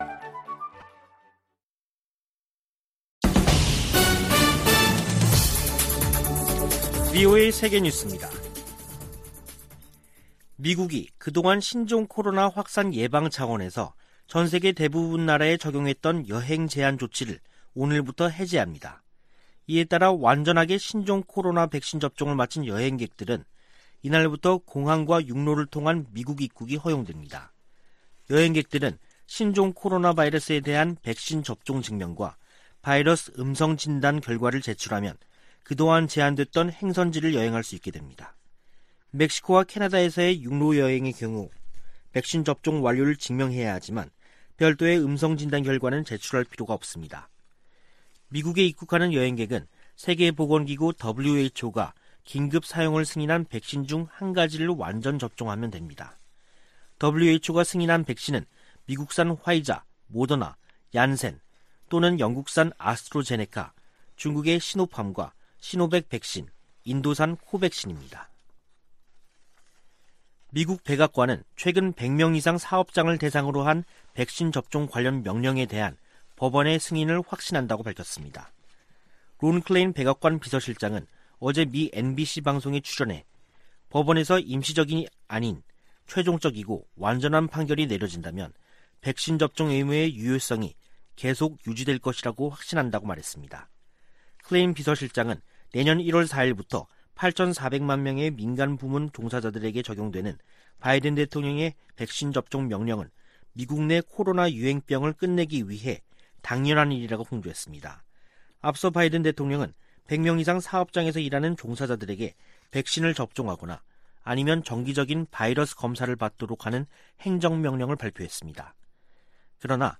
VOA 한국어 간판 뉴스 프로그램 '뉴스 투데이', 2021년 11월 8일 3부 방송입니다. 북한의 광범위한 인권 침해를 규탄하고 책임 추궁과 처벌을 촉구하는 올해 유엔 결의안 초안이 확인됐습니다. 미 국제개발처(USAID)가 북한 내 인권과 인도적 상황에 깊은 우려를 나타냈습니다.